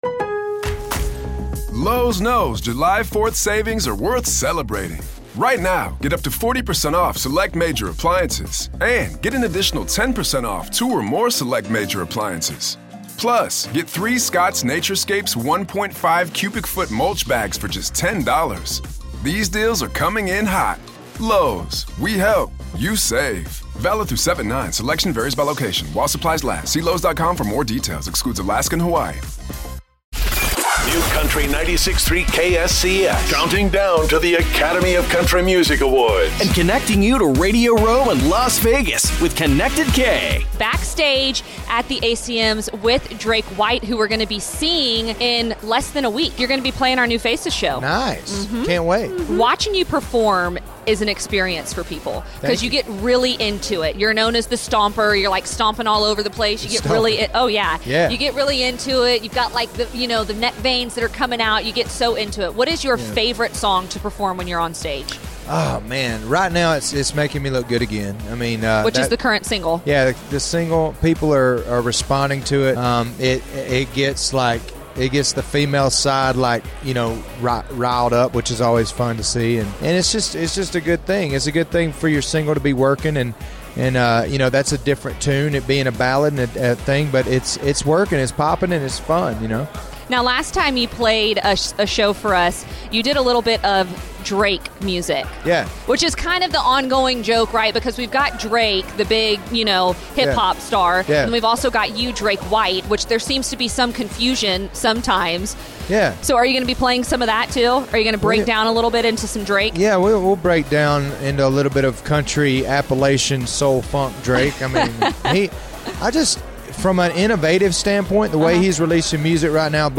ACM Interview Drake White